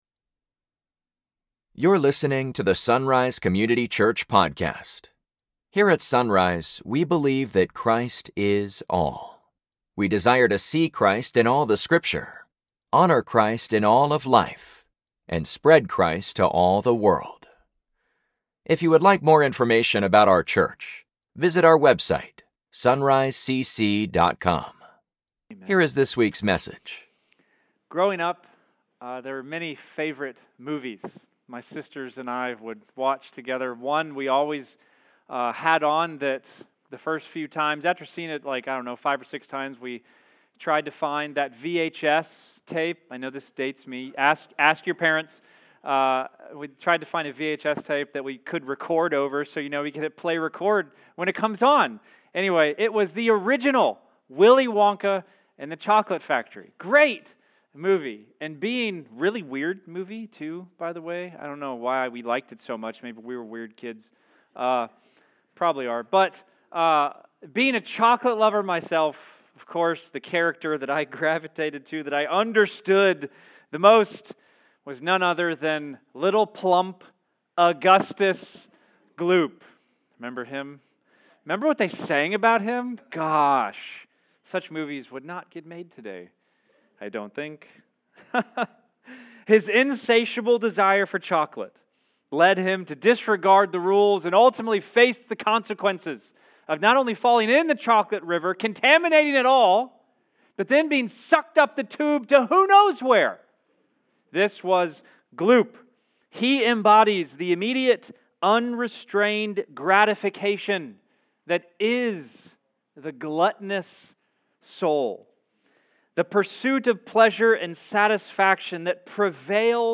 Church, in our Sins of the Church sermon series, today we come to the sin of gluttony.